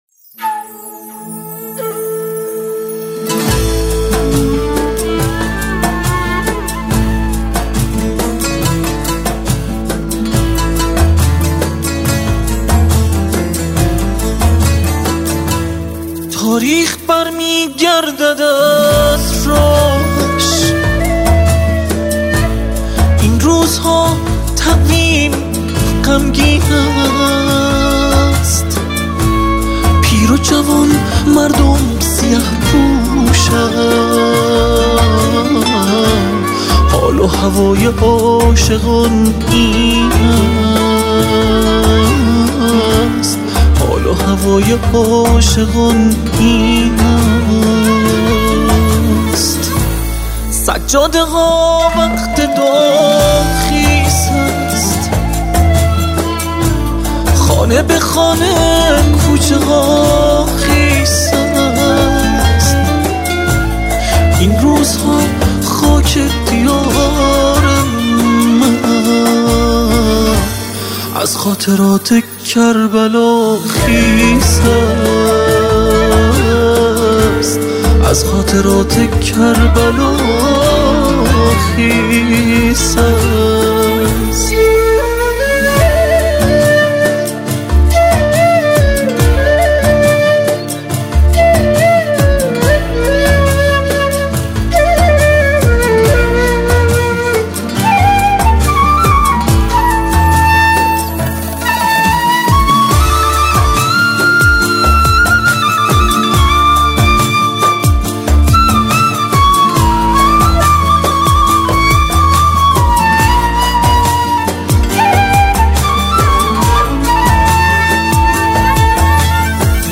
فلوت